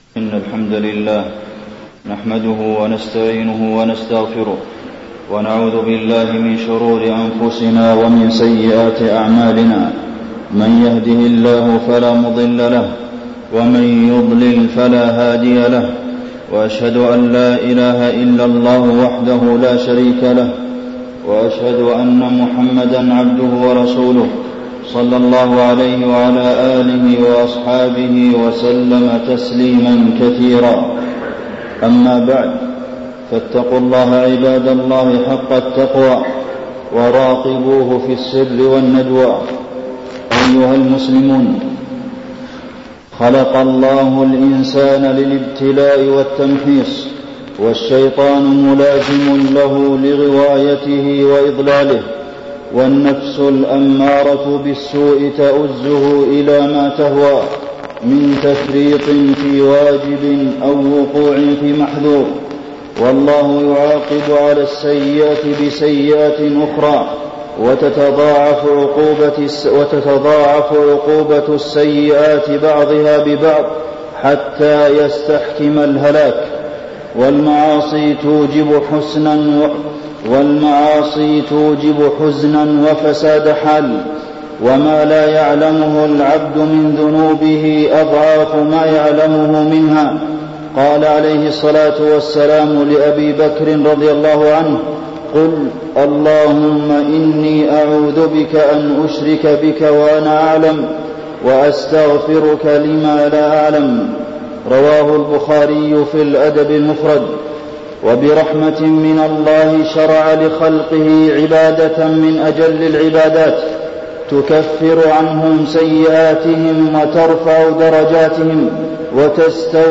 تاريخ النشر ١٥ صفر ١٤٢٦ هـ المكان: المسجد النبوي الشيخ: فضيلة الشيخ د. عبدالمحسن بن محمد القاسم فضيلة الشيخ د. عبدالمحسن بن محمد القاسم مخافة الله عز وجل